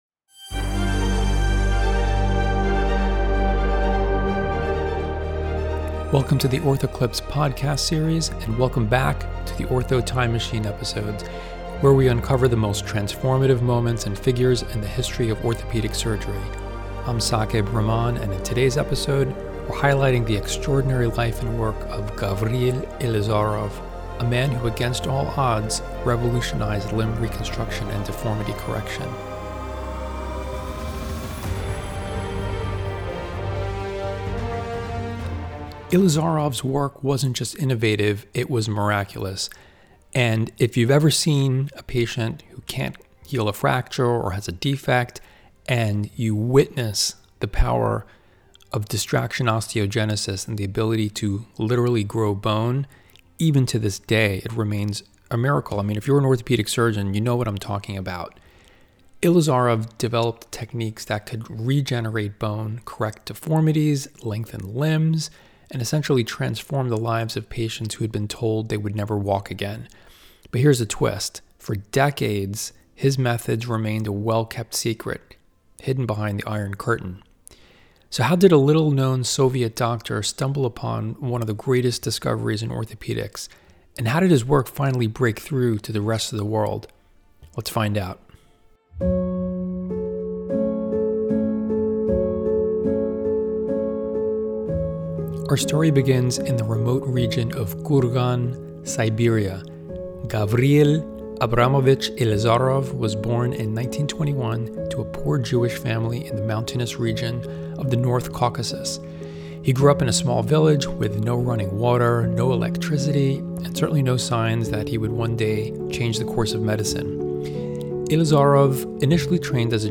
In this episode, we’re delving into the extraordinary life and work of Gavriil Ilizarov—a man who, against all odds, revolutionized limb reconstruction and deformity correction. (Note that some of the character voices are computer-generated acting and not actual footage.)